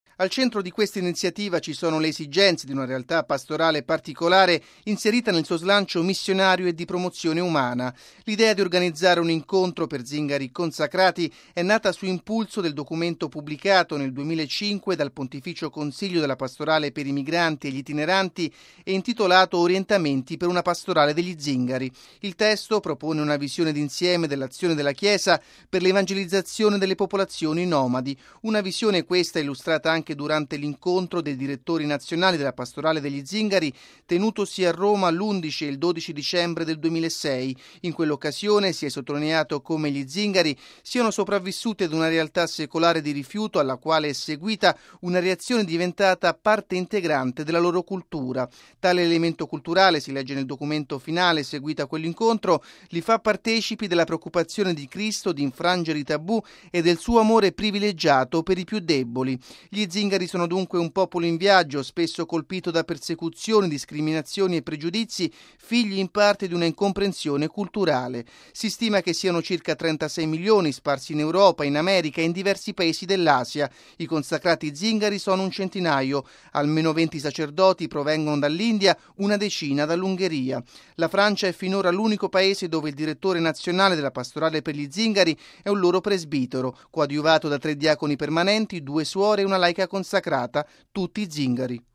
Radio Vaticana - Radiogiornale